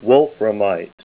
Name Pronunciation: Wolframite + Pronunciation Synonym: ICSD 15192 PDF 12-727 Wolframite Image Images: Wolframite Comments: Dark gray, tabular crystals of wolframite to 2 cm.